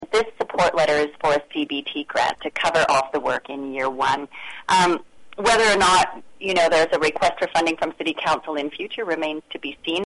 Councillor Valerie Warmington says it’s a great idea.